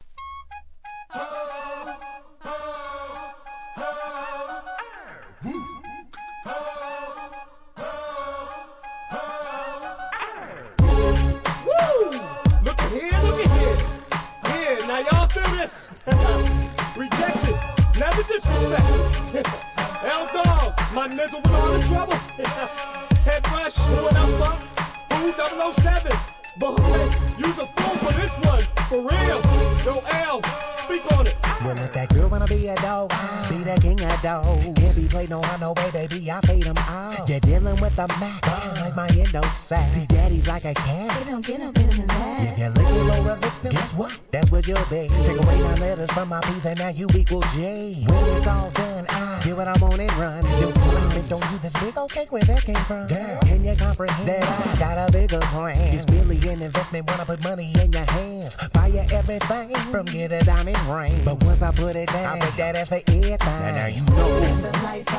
G-RAP/WEST COAST/SOUTH
歌うような独特のフロウです!